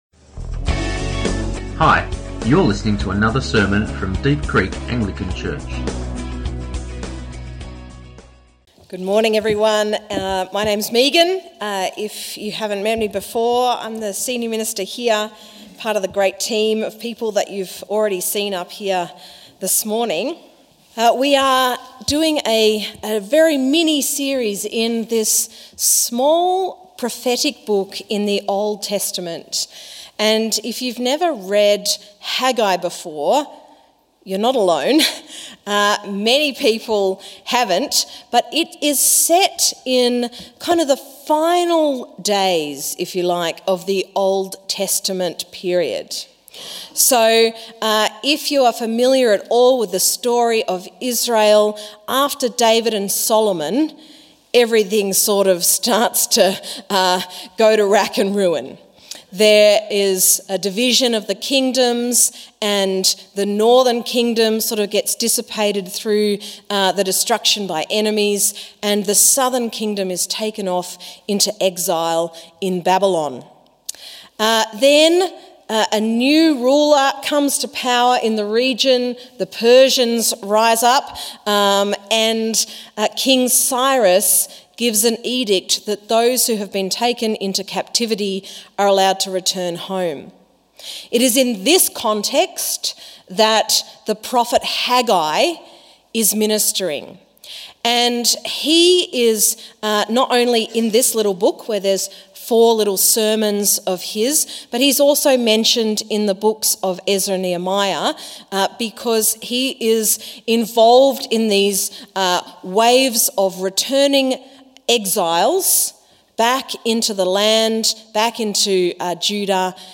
Sermons | Deep Creek Anglican Church